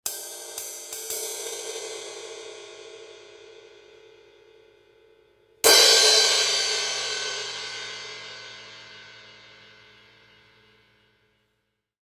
- ein Zildjian EDGE Razor Thin Crash in 17“
Je mehr aber in den gehämmerten Bereich eingegriffen wird, desto heller/ spontaner/ agressiver und „synthetischer“ klingt das Becken, tendenziell findet, meiner Meinung nach, klanglich dann eine Annäherung an den Sound von Messingbecken statt.